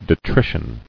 [de·tri·tion]